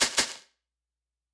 フリー効果音：シェイク
フリー効果音｜ジャンル：かんきょう、お菓子の袋をザッザッと振るときの音！
shaking_bag.mp3